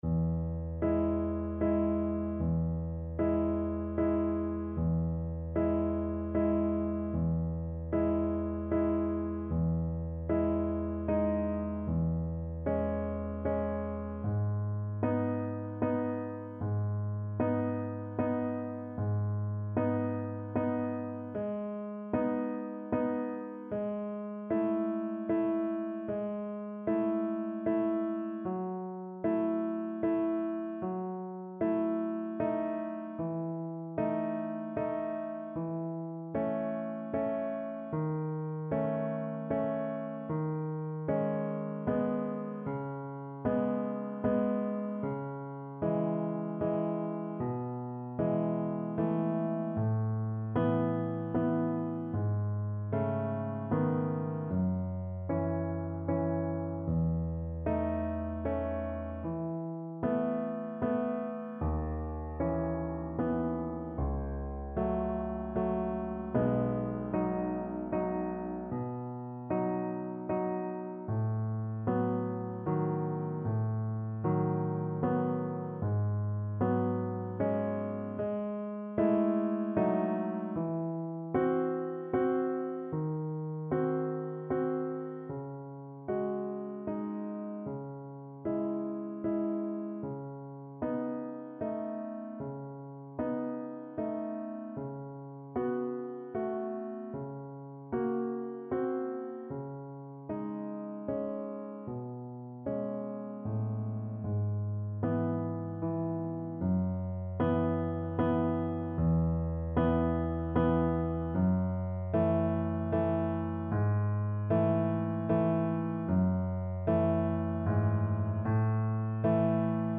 Adagio assai =76
3/4 (View more 3/4 Music)